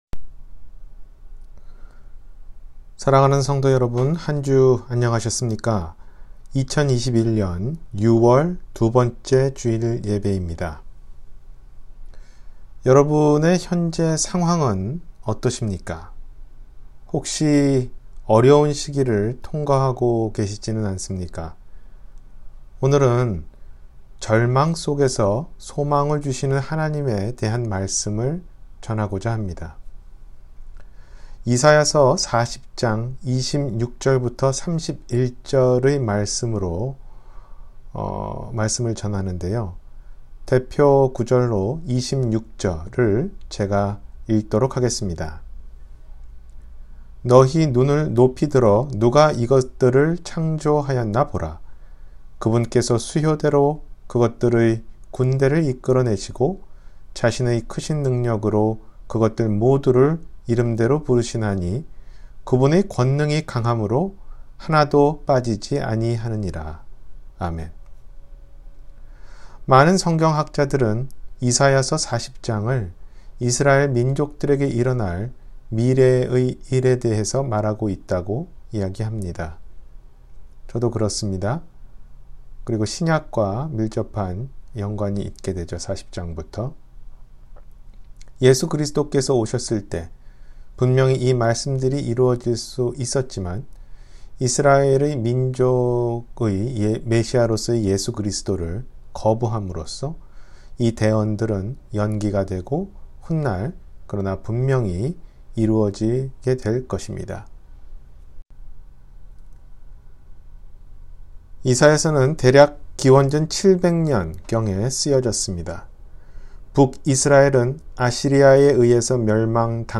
절망 속의 소망 – 주일설교